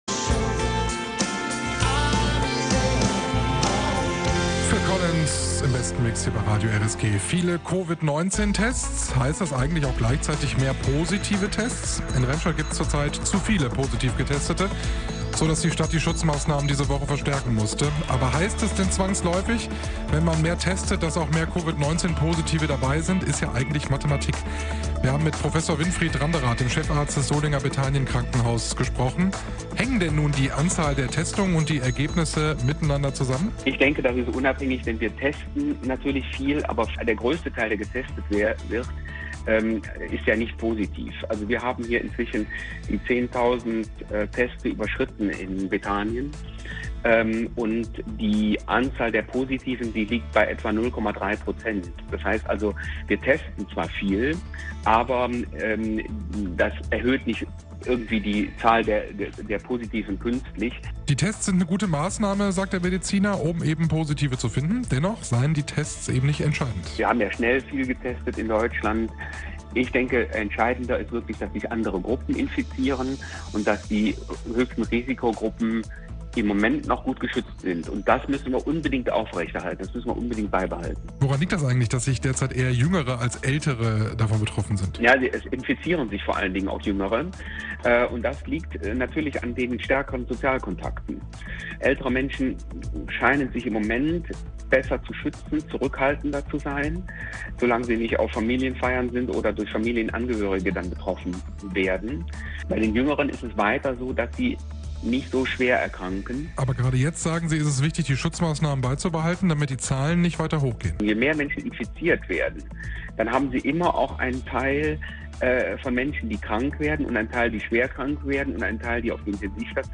im RSG-Interview.